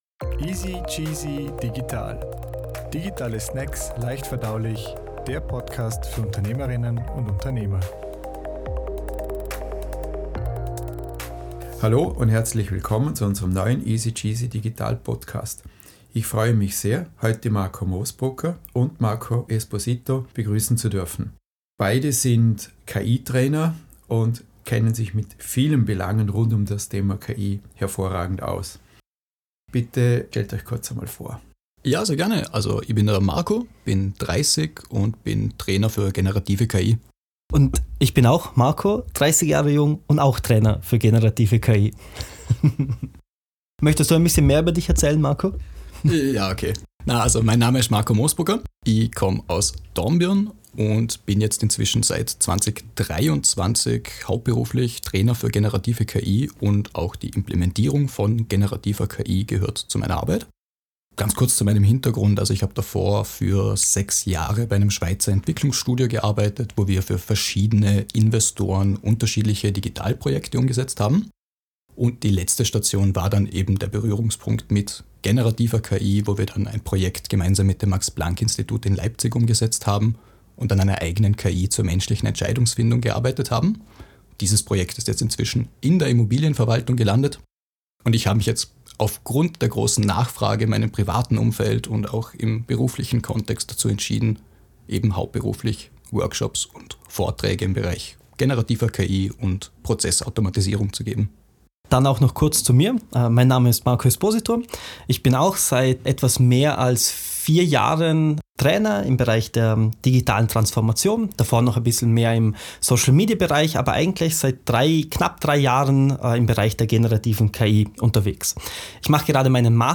Als Trainer für generative KI sprechen sie über ihre Erfahrungen, den European AI Act und ihren spannenden Ansatz: den KI-Führerschein. Ein Gespräch über Chancen, Herausforderungen und den richtigen Umgang mit Künstlicher Intelligenz im Arbeitsalltag.